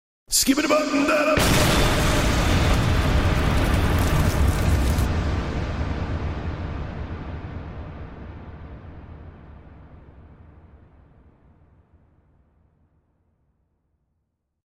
Skibidi Bop Mm Dada Sound Effect (REVERB)